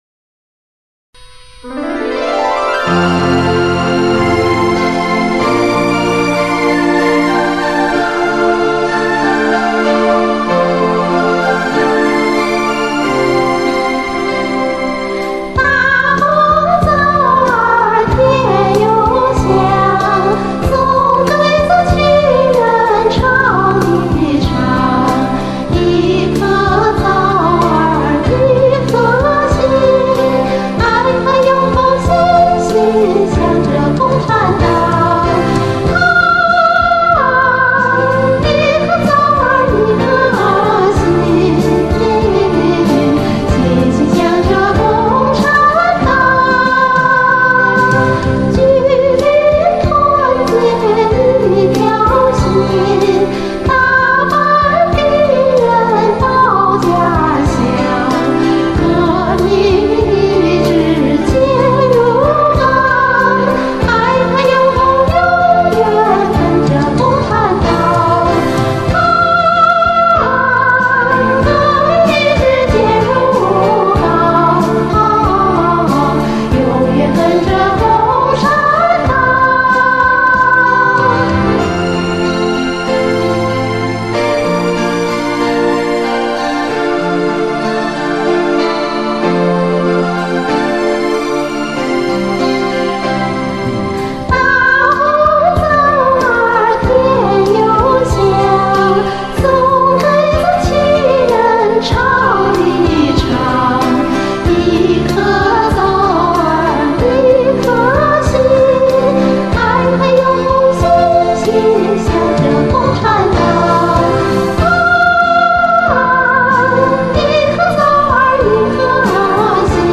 随便在网上找了这首老红歌的伴奏，因为目的是录音尝试，自然是找一首自己原本就会的歌。
连录了三遍后，自己觉得已经有了合唱的效果了，于是，就连同伴奏混合下载保存了。
所以，这歌从来没打算贴山上，而且，这又没唱和声，就是个简单的齐唱。
忙活了一晚上，想给这歌加和声，可是发现效果不佳，因为这伴奏杂音太大，不适合唱和声。